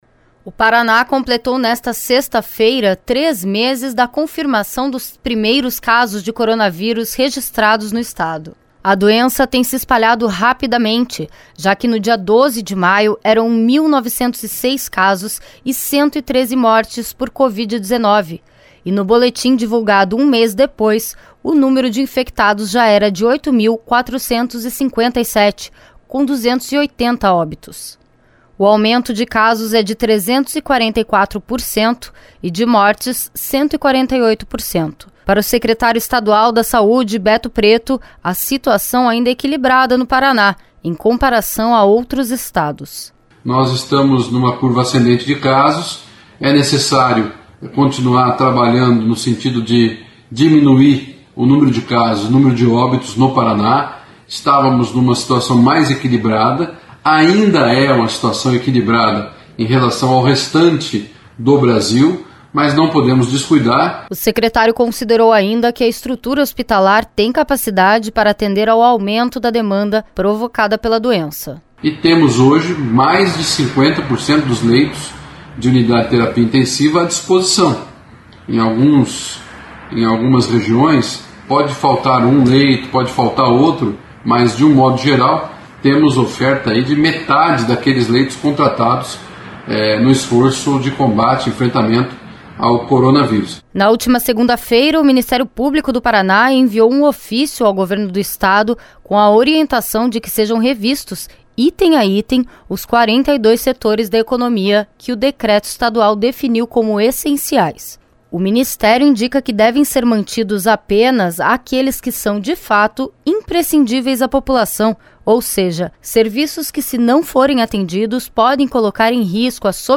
Para o secretário estadual da Saúde, Beto Preto, a situação ainda é equilibrada no Paraná, em comparação a outros estados.
Segundo o secretário estadual da Saúde, Beto Preto, uma nova estratégia já está sendo traçada para o combate à Covid-19 no Paraná.